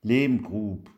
hochdeutsch Gehlbergersch
Die Lehmgrube  Lehmgruub